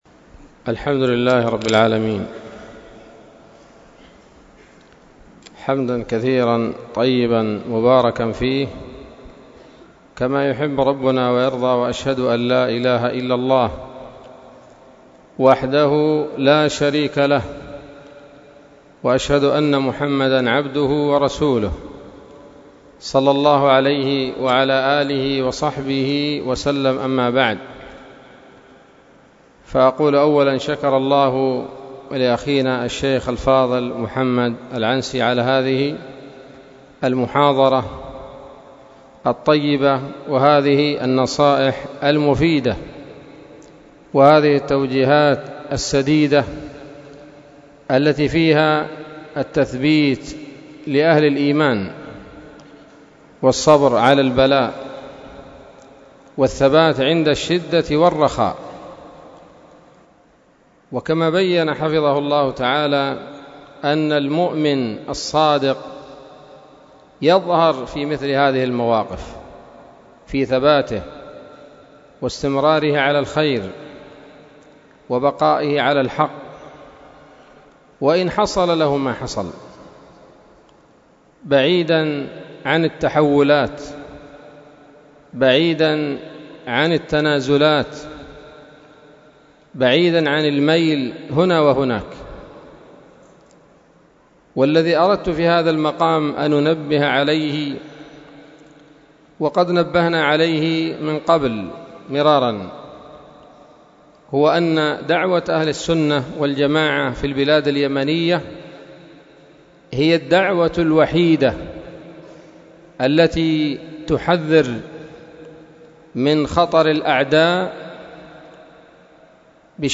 كلمة قيمة بعنوان: (( التحذير من المنظمات )) ليلة الجمعة 7 ربيع الآخر 1443هـ، بدار الحديث السلفية بصلاح الدين